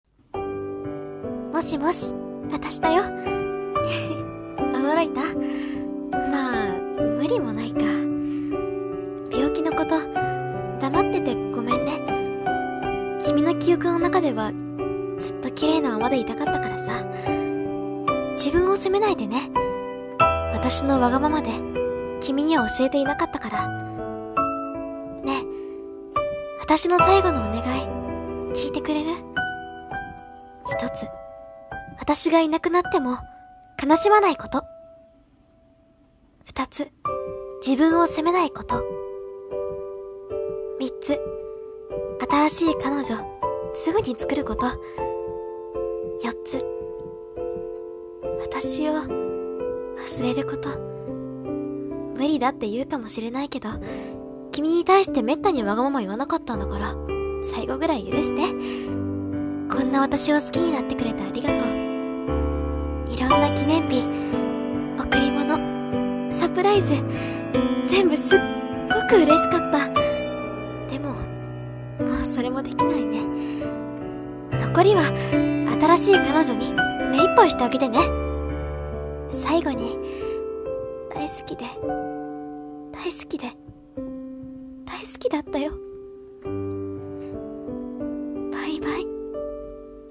【一人声劇】最後の願い